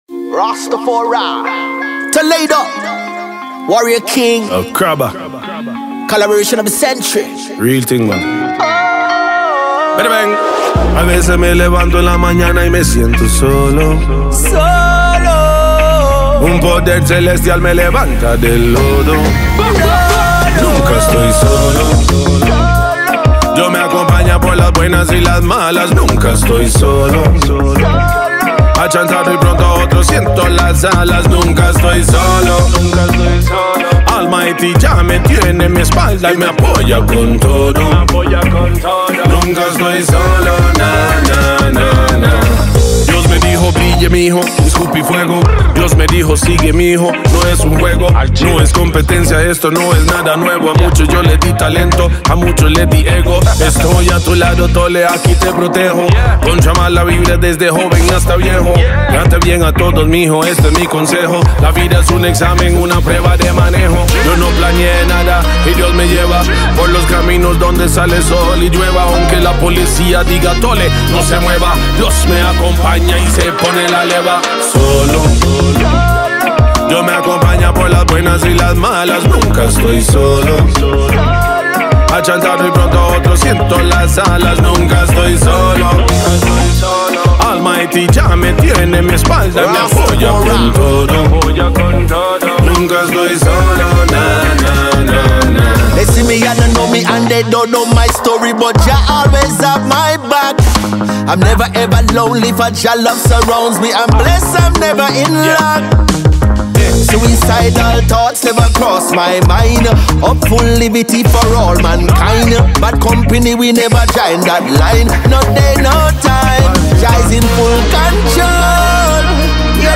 is a powerful fusion of reggae and dancehall
blend smooth melodies with uplifting lyrics